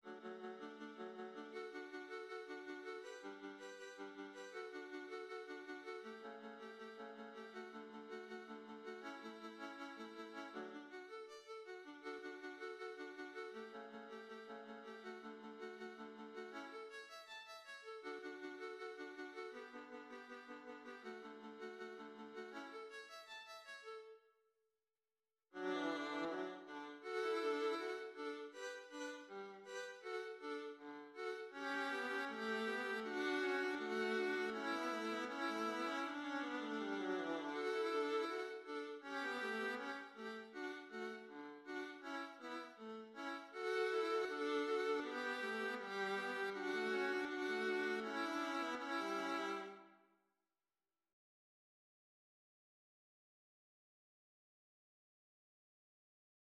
(entire two verses)